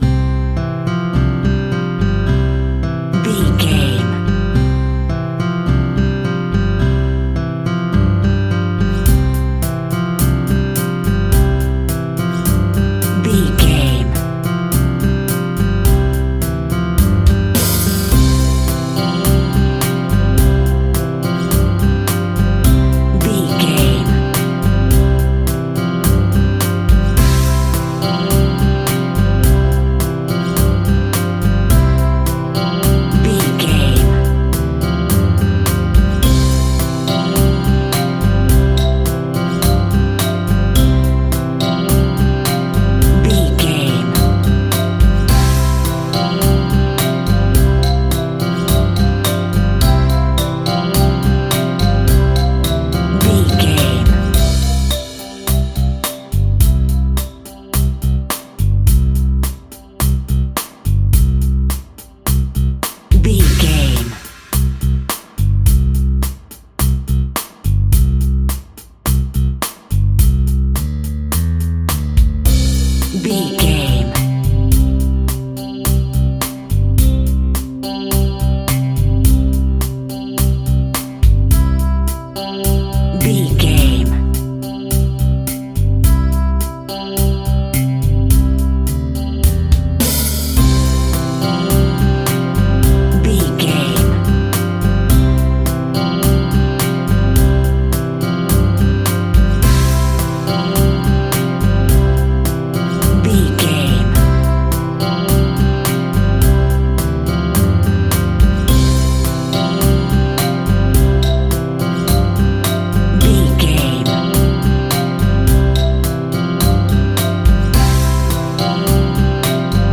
Ionian/Major
pop rock
indie pop
fun
energetic
uplifting
synths
drums
bass
guitar
piano
keyboards